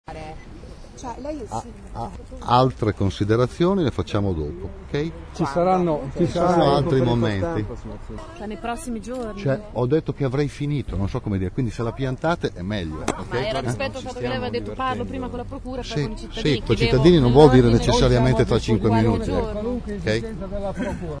Si abbandona al nervosismo il sindaco di Bologna, Flavio Delbono, di fronte alle insistenze dei cronisti che, all’uscita della Procura, gli chiedevano chiarimenti su alcuni elementi dell’inchiesta (come il bancomat) ma anche, soprattutto, risposte politiche ai cittadini.
I cronisti in coro gli dicono: “Aveva detto che dopo aver parlato con la Procura avrebbe spiegato ai cittadini”.